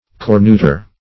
Cornutor \Cor*nu"tor\ (-t?r), n. A cuckold maker.